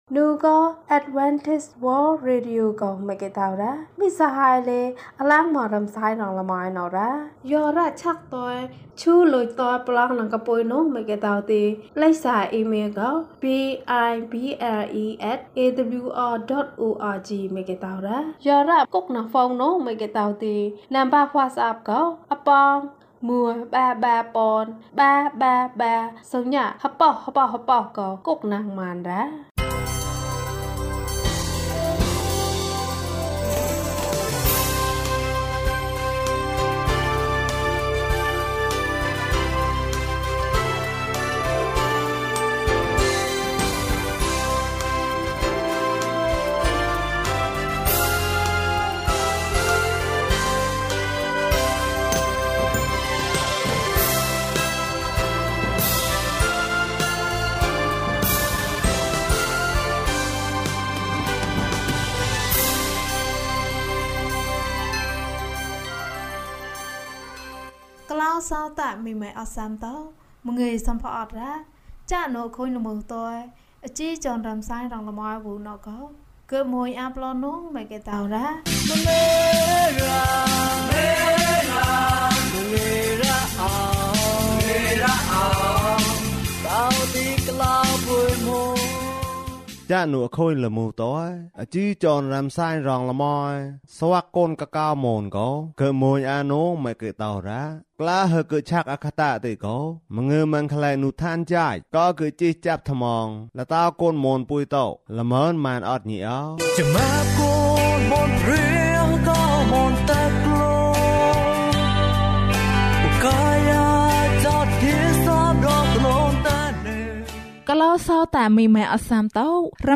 အပြစ်များကင်းဝေးကြပါစေ။ ကျန်းမာခြင်းအကြောင်းအရာ။ ဓမ္မသီချင်း။ တရားဒေသနာ။